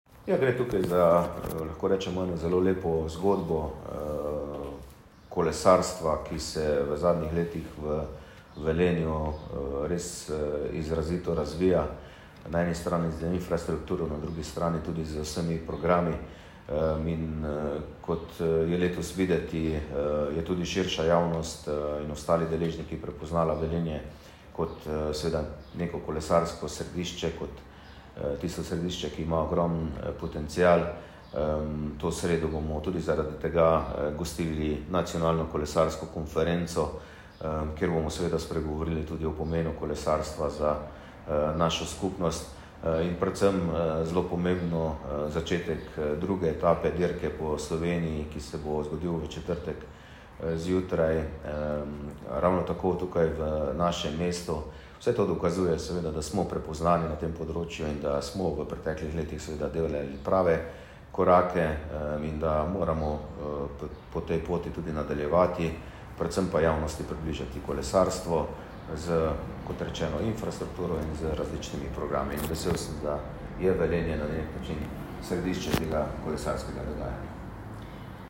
Tonska izjava župana